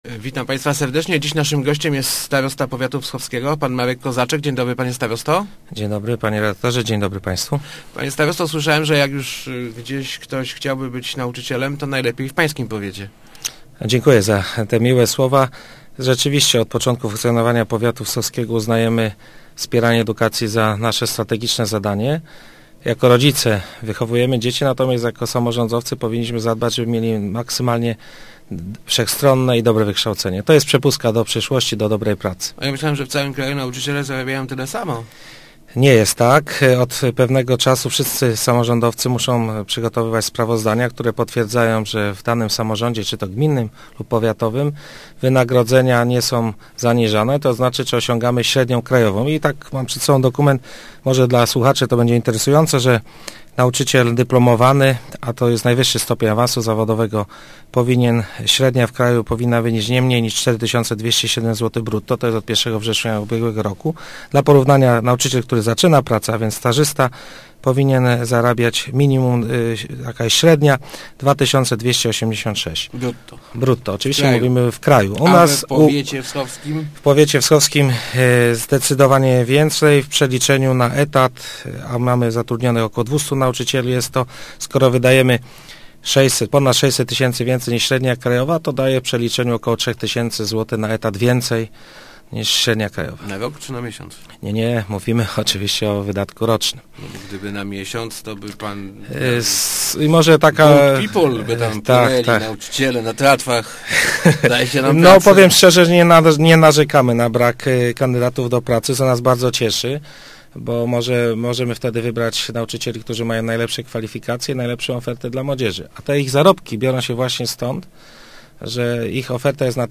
Średnio trzy tysiące złotych więcej – mówił w Rozmowach Elki starosta Marek Kozaczek. Dzieje się tak dzięki szerokiej ofercie zajęć pozalekcyjnych – samorząd nie wprowadził tu żadnych limitów, co oznacza, że można je zorganizować praktycznie z każdego przedmiotu.